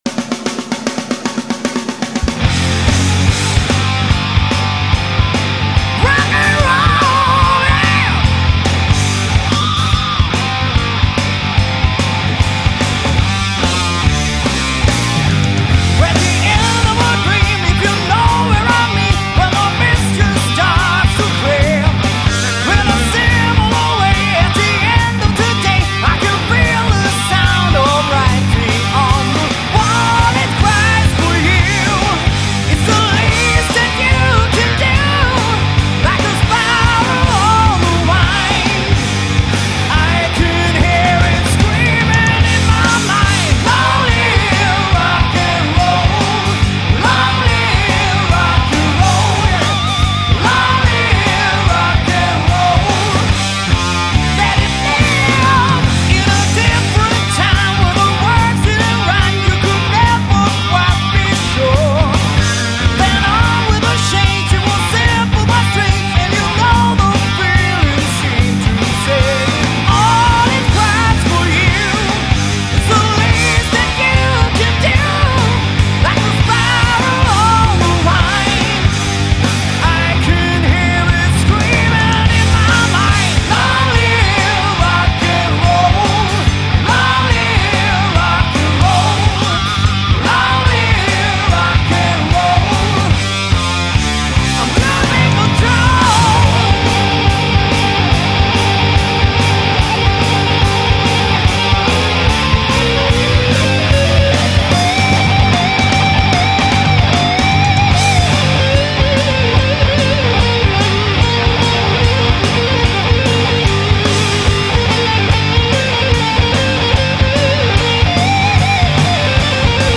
Gitarr
Trummor